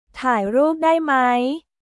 タイ・ループ・ダイ・マイ？